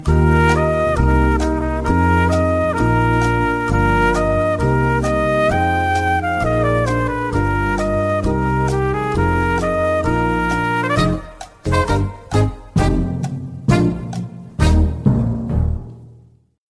Play, download and share Countdown 15 seconds original sound button!!!!
countdown-15-seconds.mp3